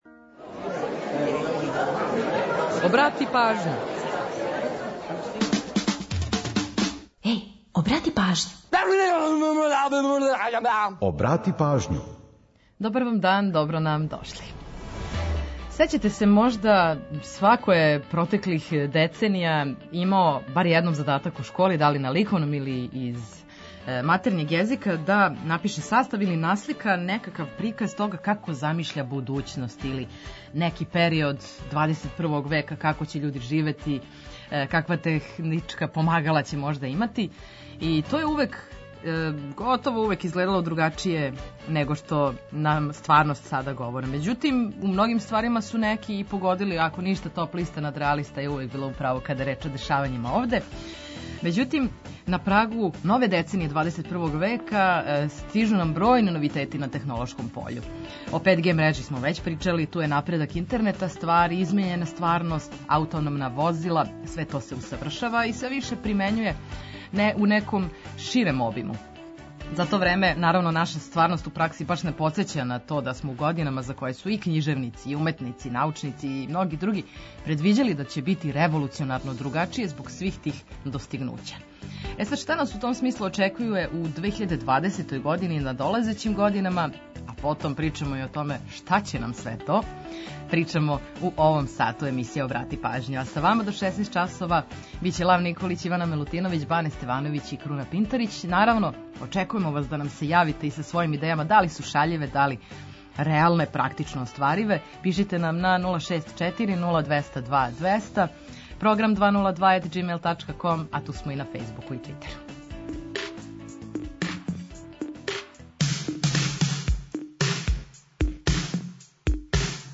Ту су и музичке теме којима подсећамо на приче иза песама и рођендане музичара и албума, уз пола сата резервисаних само за музику из Србије и региона.